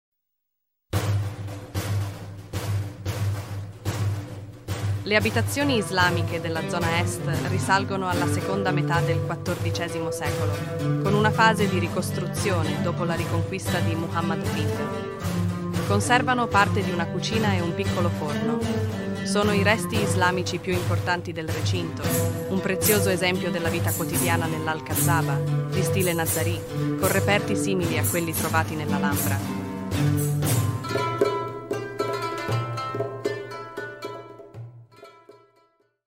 Ruta audioguiada
audioguia-italiano-qr9.mp3